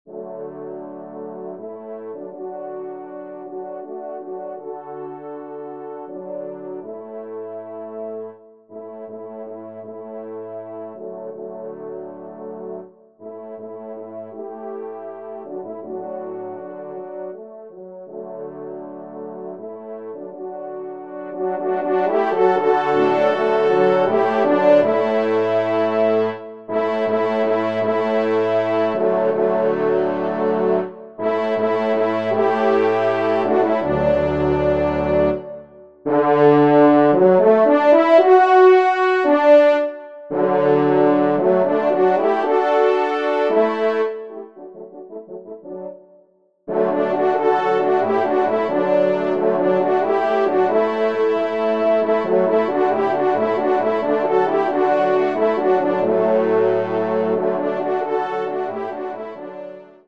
Genre :  Divertissement pour quatre Trompes ou Cors en Ré
ENSEMBLE